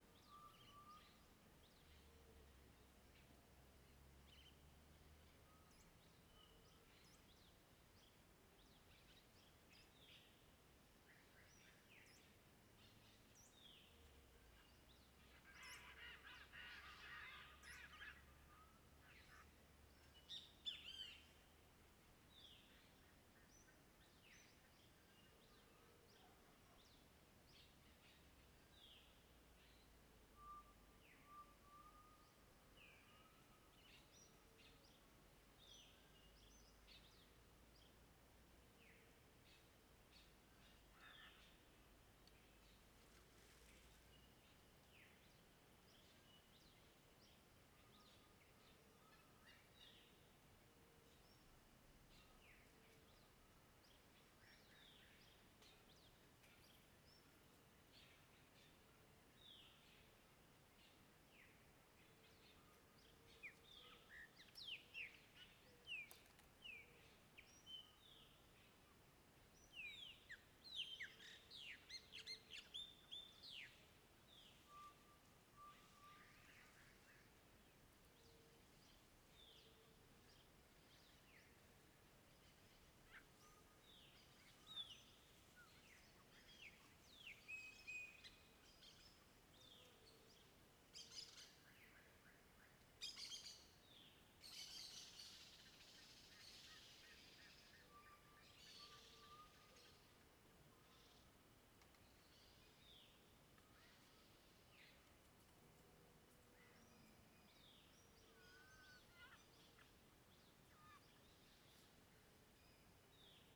CSC-05-027-LE - Ambiencia mata amanhecer cachoeira bem longe com varios passaros diferentes.wav